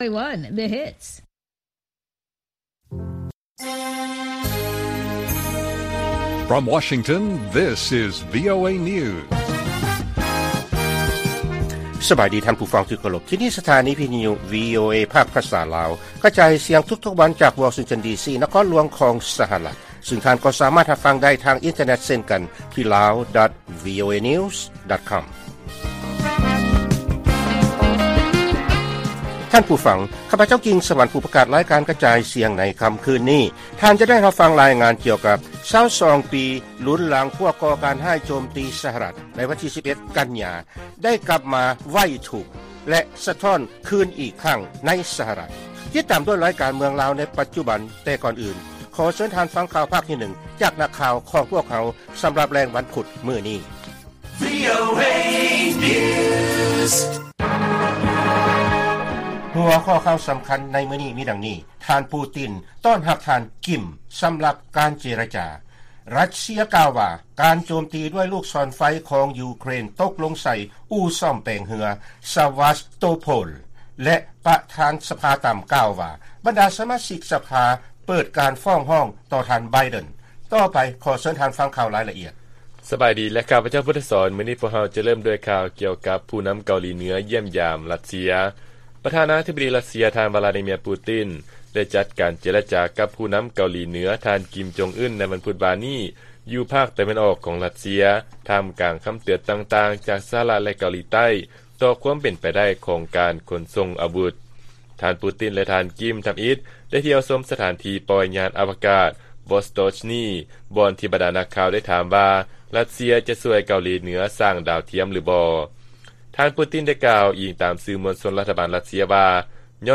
ລາຍການກະຈາຍສຽງຂອງວີໂອເອ ລາວ: ທ່ານ ປູຕິນ ຕ້ອນຮັບທ່ານ ກິມ ສຳລັບການເຈລະຈາ ທ່າມກາງຄຳເຕືອນຕໍ່ຂໍ້ຕົກລົງຂາຍອາວຸດ